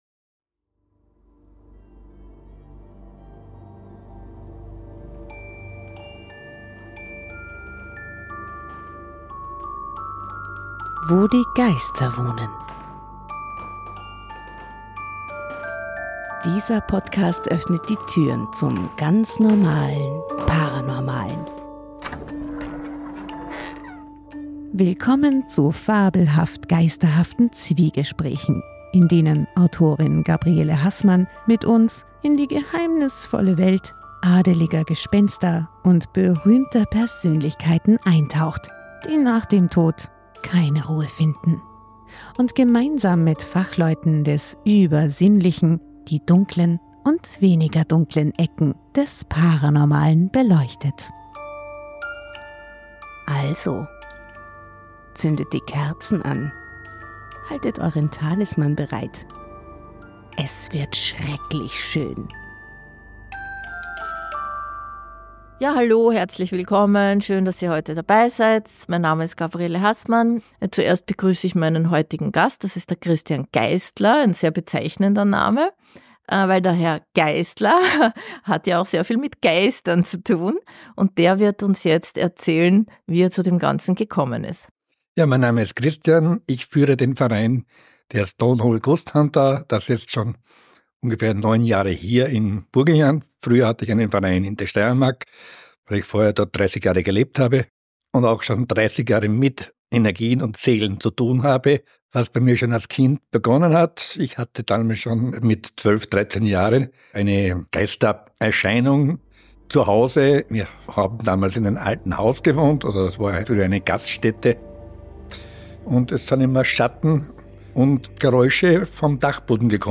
in spannenden Zwiegesprächen Zuhörer*innen durch die Welt der Geister. In fabelhaft geisterhaftem Plauderton